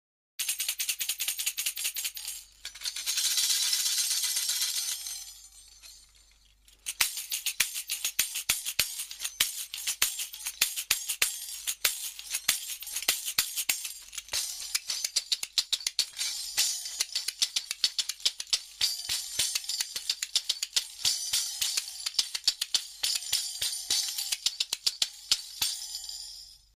シャカシャカ
棒の中央を持ち　もう片方の手にあてたり　金属を指ではじいて　リズムをきざみます